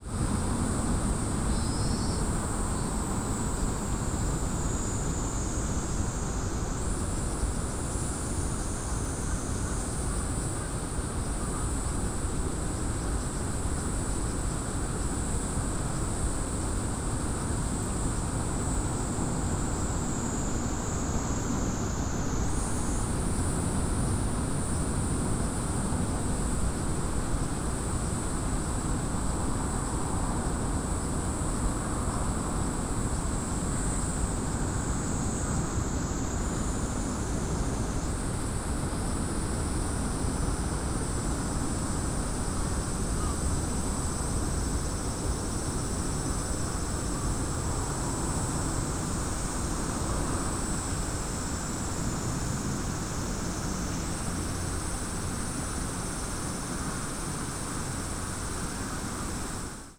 Fukushima Soundscape: Shinhama Park